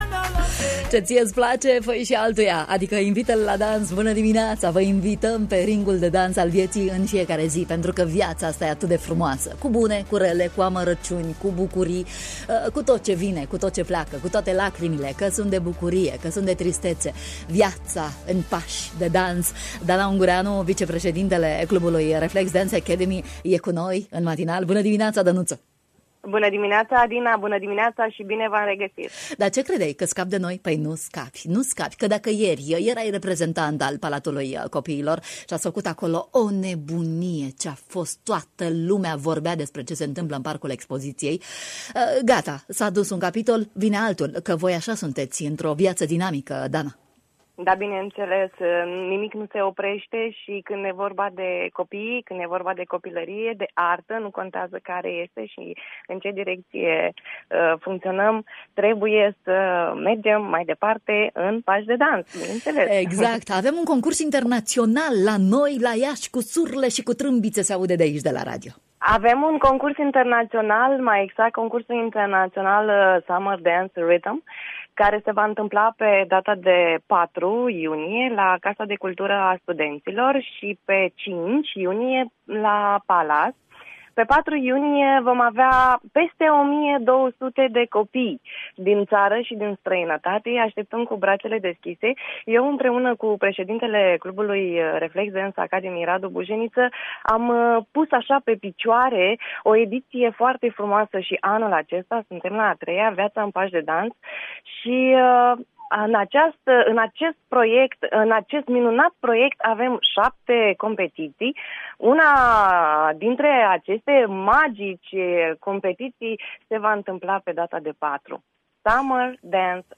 în direct la matinalul de la Radio România Iaşi